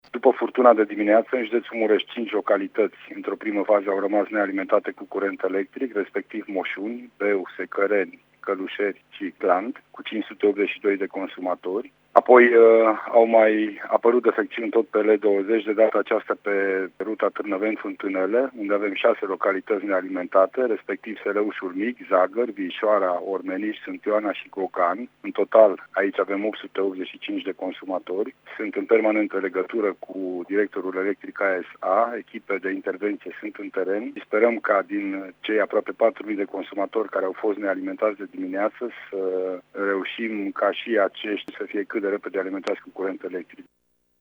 Echipele de la Electrica se află în teren, iar prefectul județului, Lucian Goga a declarat că speră ca până diseară situația să fie remediată: